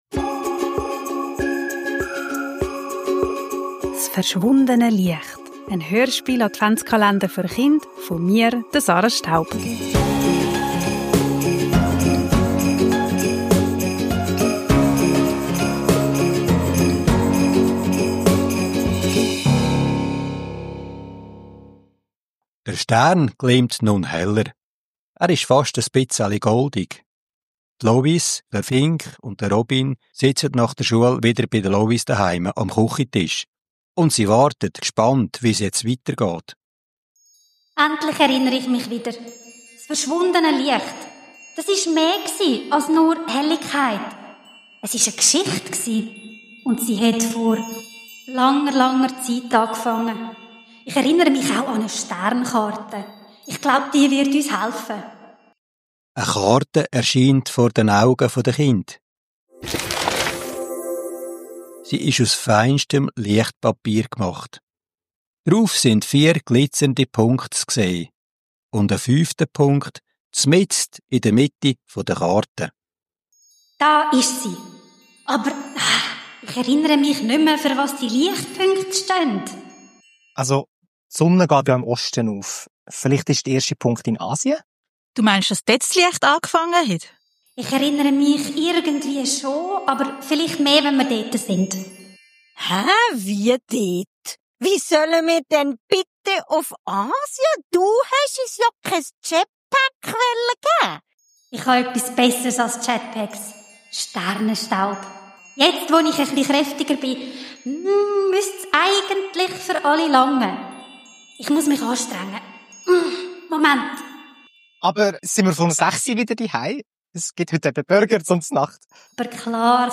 s' verschwundene Liecht - en Adventshörspiel-Kalender für Chind
Kinder, Advent, Hörspiel, Weihnachten, Kindergeschichte